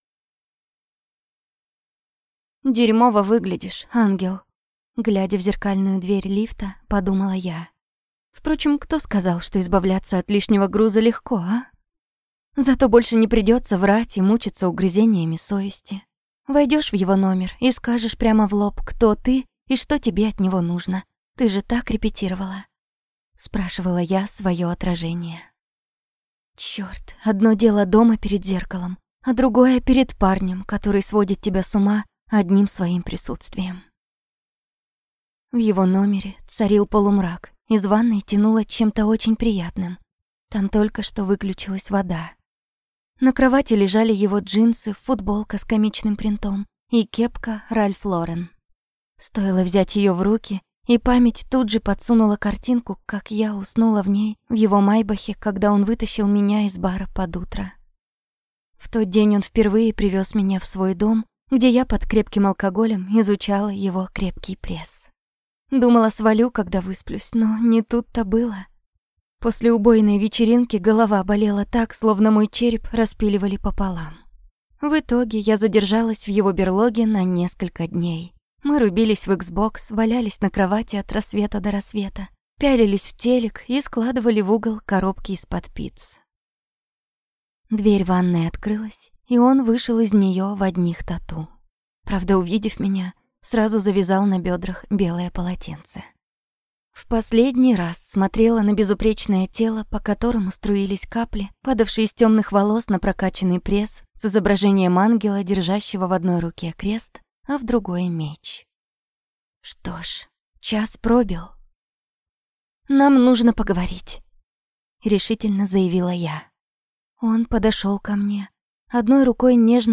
Аудиокнига Крылья для Ангела | Библиотека аудиокниг
Прослушать и бесплатно скачать фрагмент аудиокниги